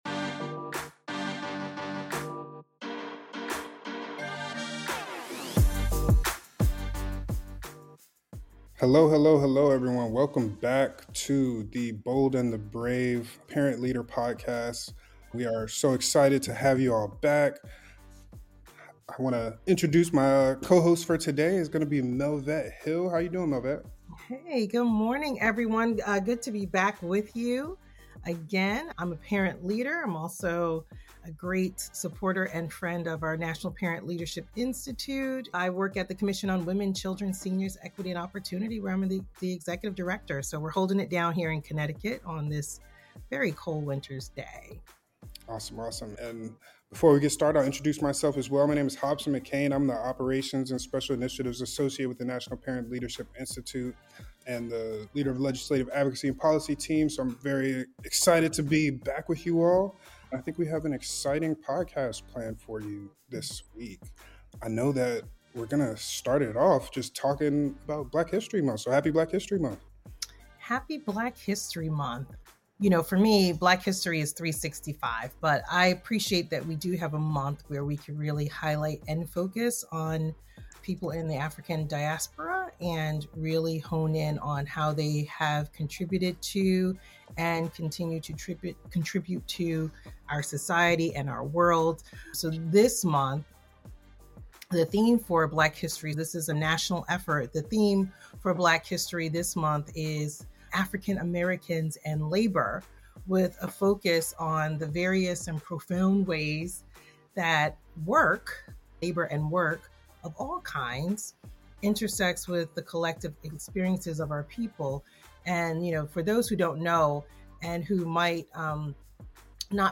A Conversation on Housing Inequities & Black History Month